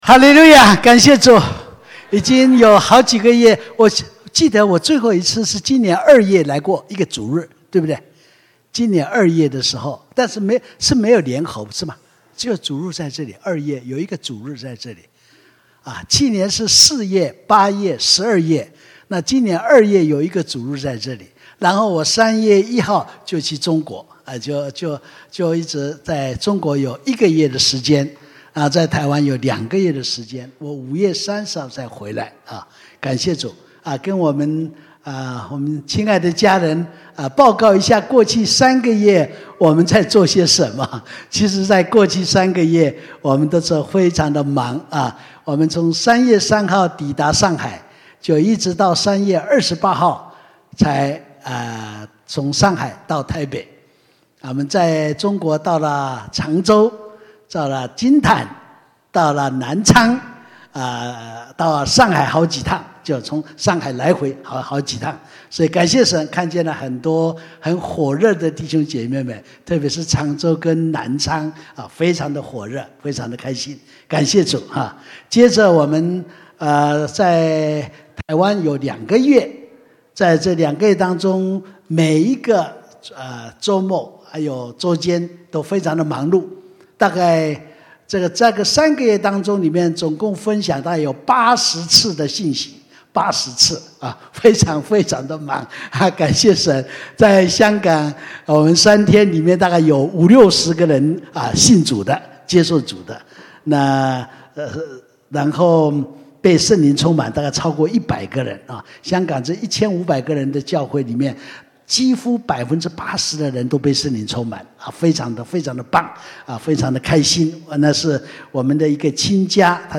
天国的福音布道大会 (一)